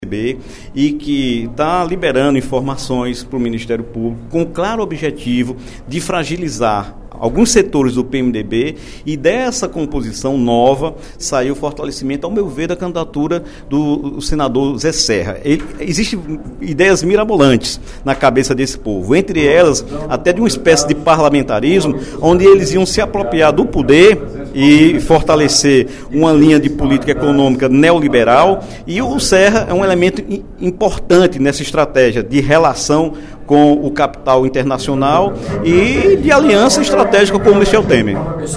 O deputado Dr. Santana (PT) fez pronunciamento nesta quarta-feira (25/05), durante o primeiro expediente da sessão plenária, para avaliar as gravações de Sérgio Machado, ex-presidente da Transpetro, divulgadas pelo jornal Folha de São Paulo.